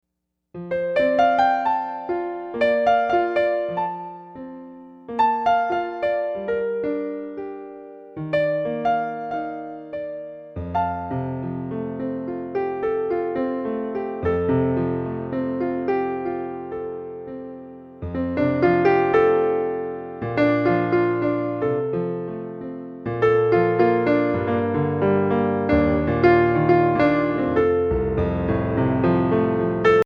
Instrumental Album Download